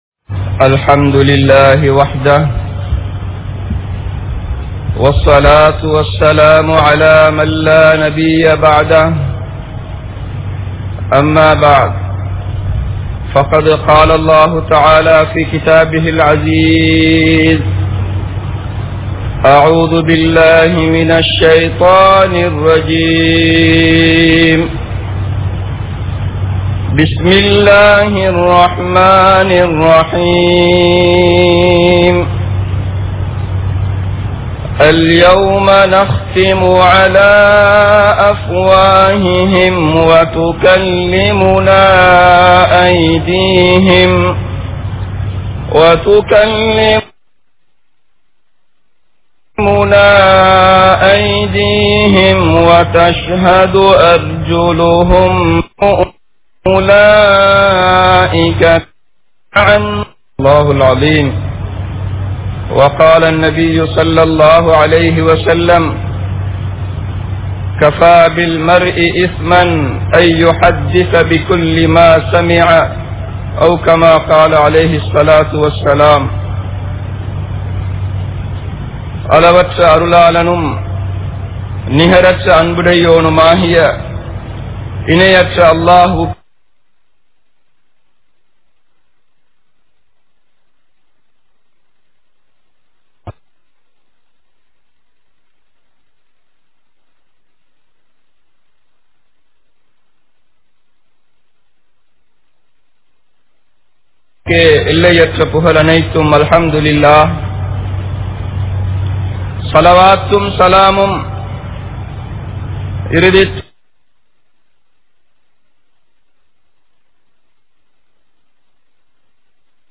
Manithanin Seyalhal (மனிதனின் செயல்கள்) | Audio Bayans | All Ceylon Muslim Youth Community | Addalaichenai
Colombo 03, Kollupitty Jumua Masjith